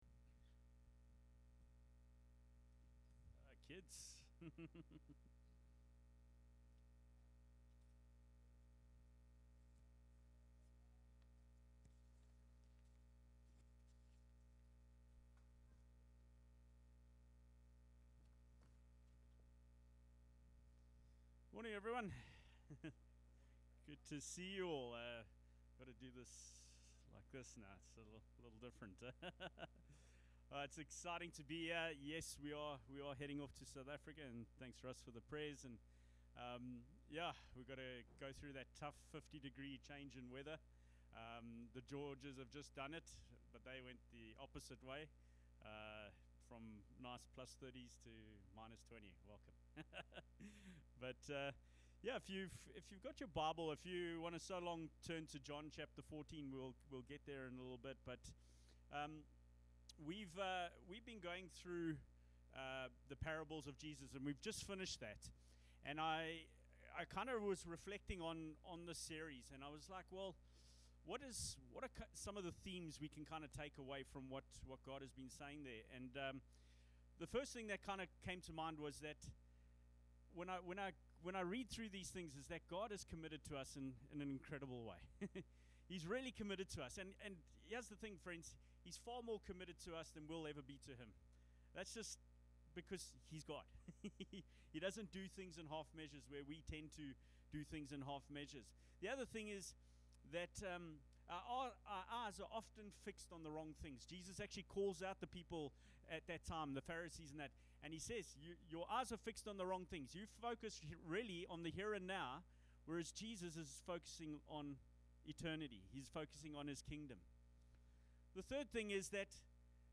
Sermons | Explore Life Church